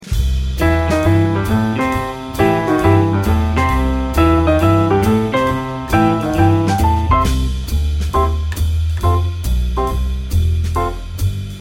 with pizzazz